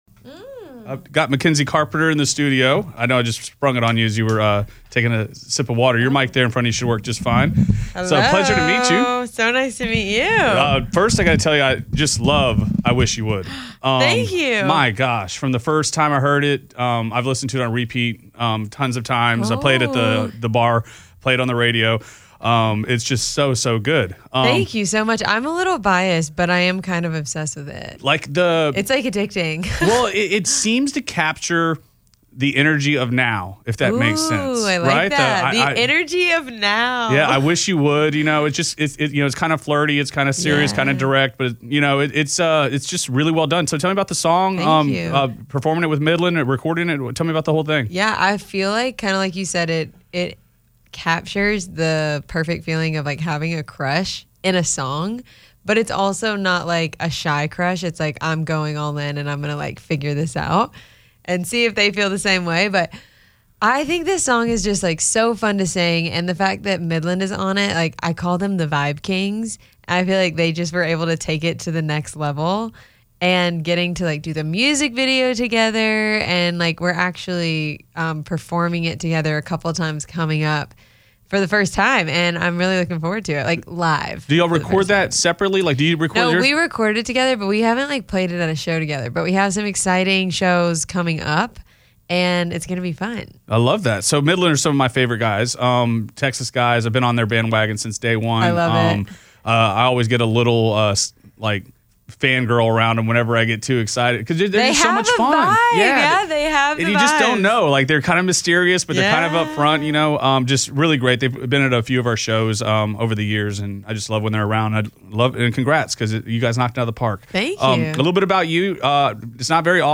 Acclaimed singer-songwriter, Mackenzie Carpenter stopped by The Bull Studio at Audacy Houston to chat about her new single featuring the group, Midland, called "I Wish You Would." We talk about the idea behind the song, her incredible contributions in songwriting for Megan Moroney, Lily Rose and more.